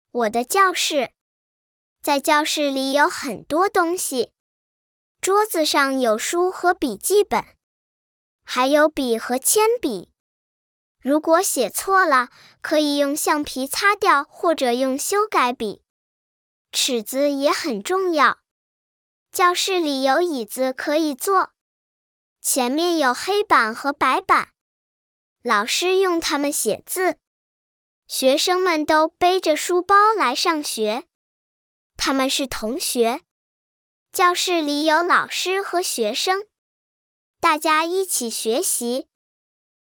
B.-Reading-of-Wo-de-Jiaoshi.mp3